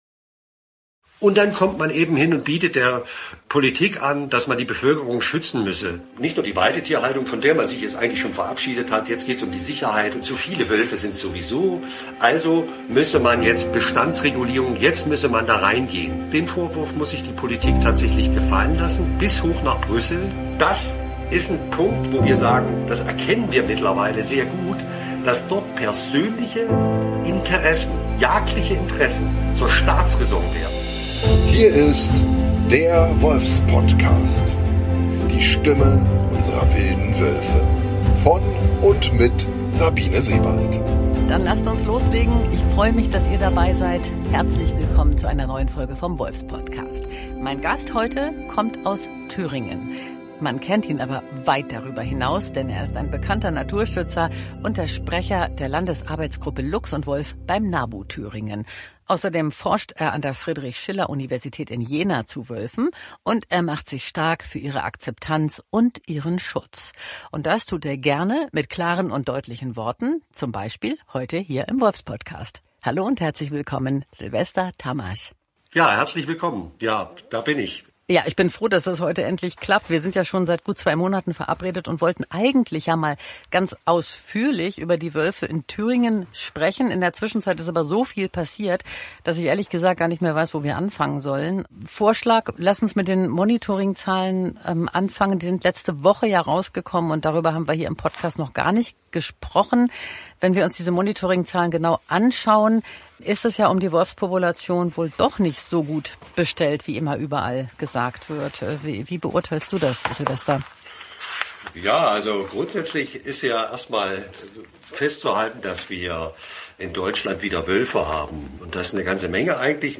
Ein intensives, ehrliches Gespräch über Artenschutz, politische Verantwortung und die Frage, warum Haltung wichtiger ist denn je, wenn der Wolf – und mit ihm unsere Natur – zunehmend unter Beschuss gerät.